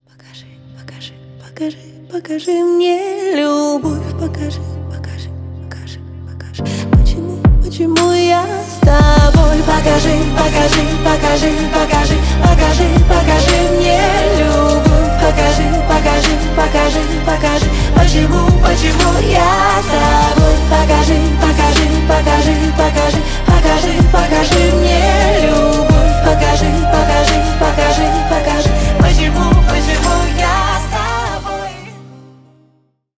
Поп Музыка
спокойные # тихие